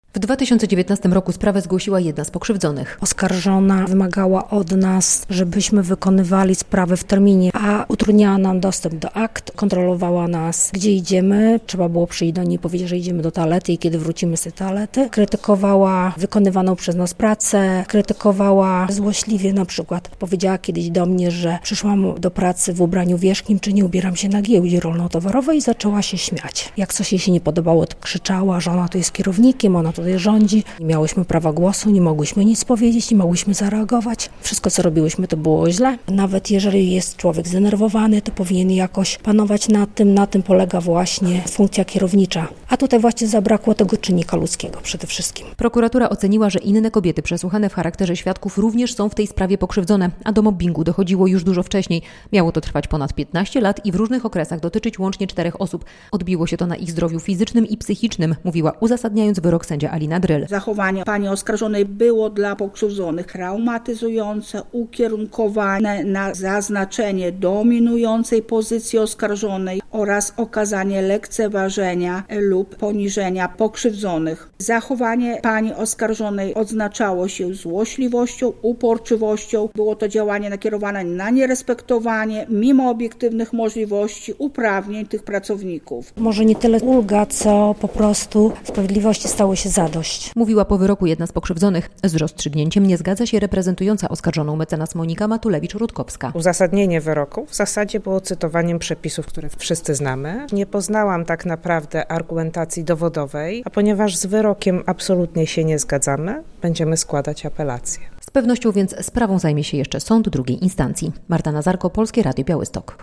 Wyrok za mobbing - relacja